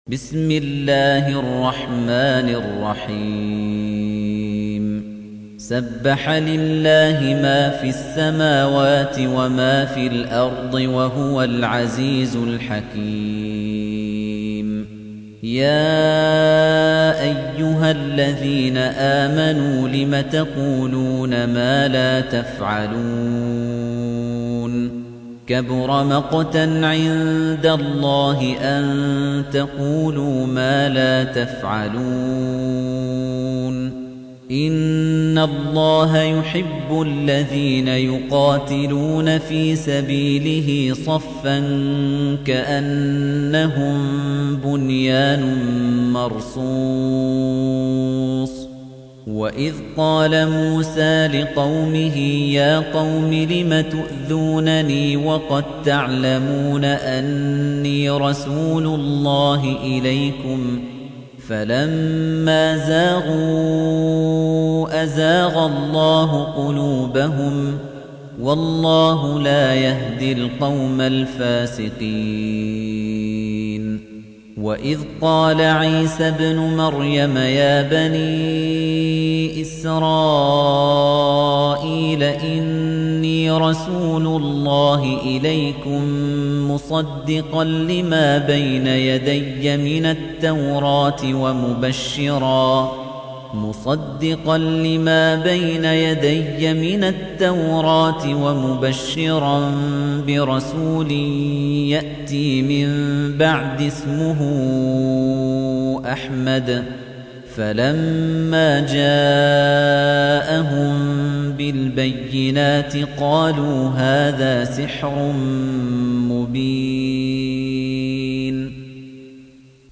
تحميل : 61. سورة الصف / القارئ خليفة الطنيجي / القرآن الكريم / موقع يا حسين